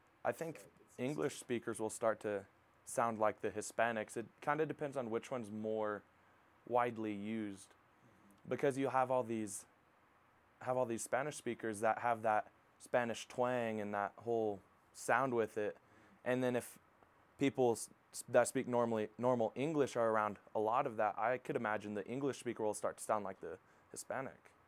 This audio sample illustrates pre-nasal raising. The young European American male speaker from Liberal says his English vowels — especially the word "Spanish" — in a Spanish-style pronunciation.